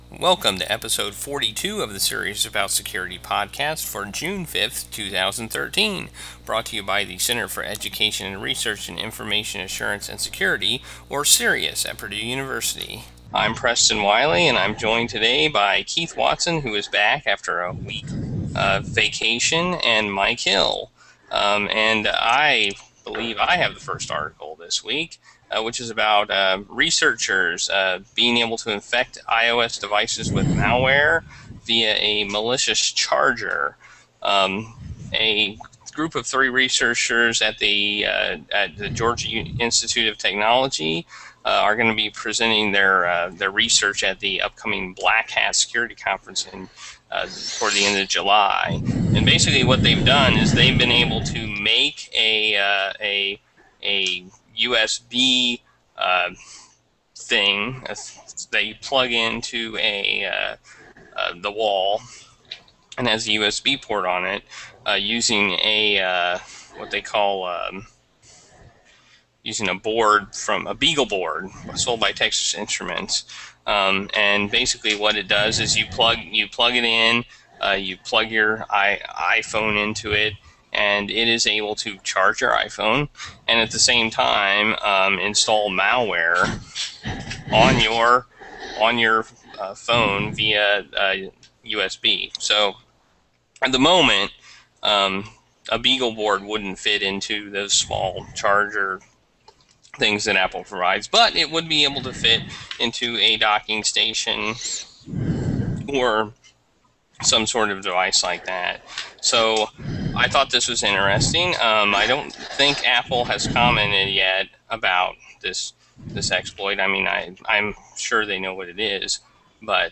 Google+ Hangout